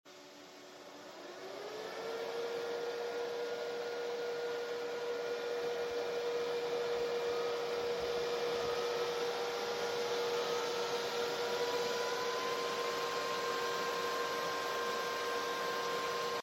it sounds like a jet sound effects free download
it sounds like a jet engine